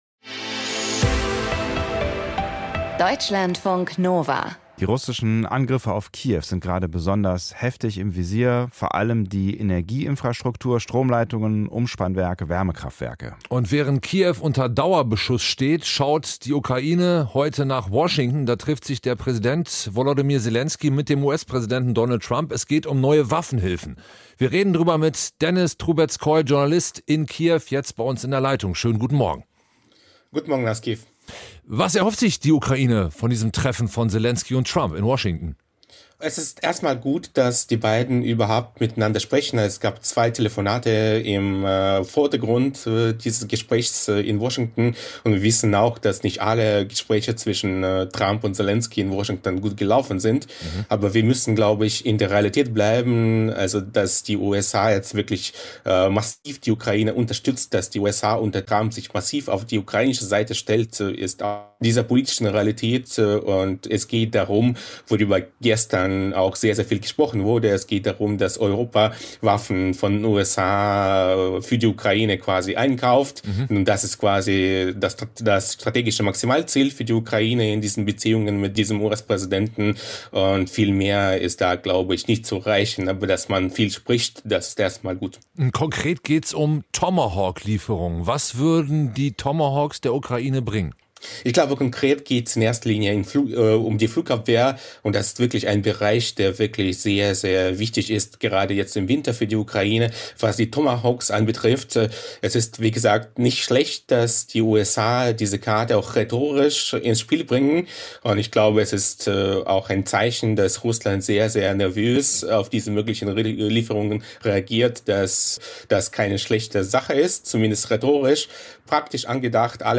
Die Politologin Claudia Major glaubt, Putin könnte Trump davon abgebracht haben, Tomahawk-Marschflugkörper an die Ukraine zu liefern.